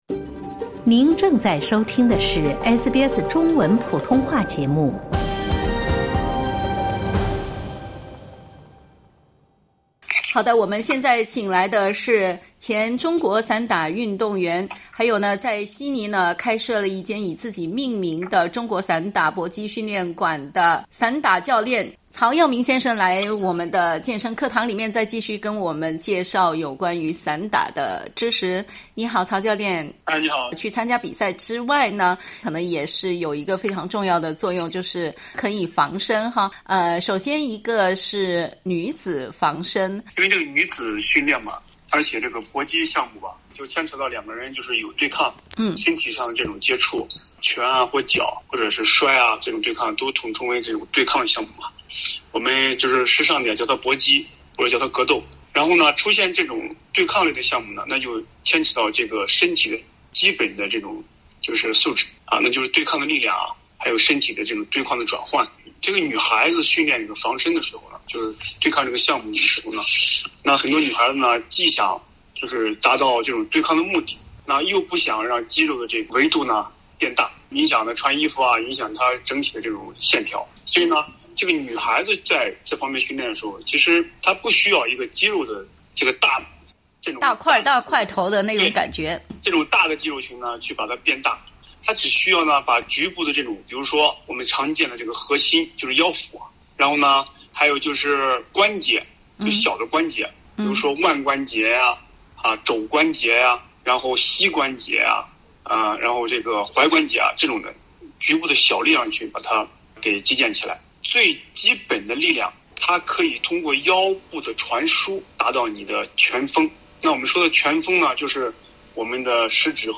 （點擊圖片收聽完整寀訪）